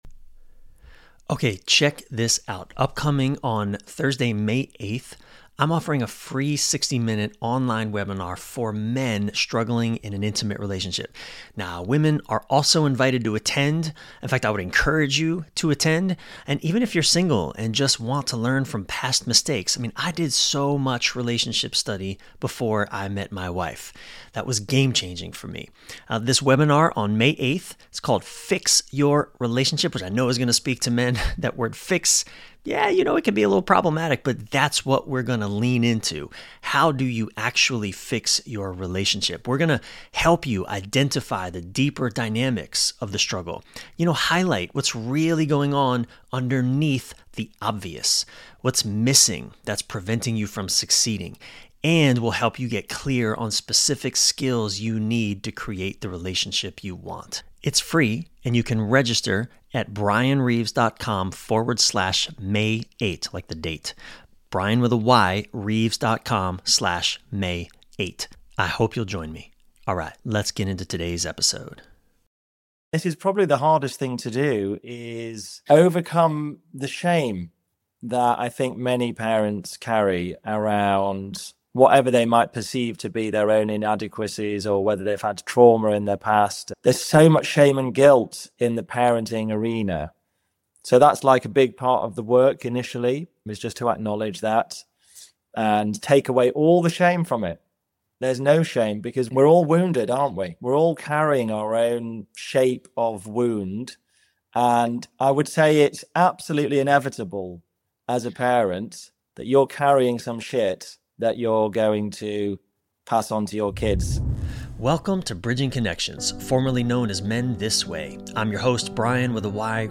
as together they tackle the essential, raw conversations we all need to be having on the most important subjects our lives: relationships, purpose, health, spirituality, and more.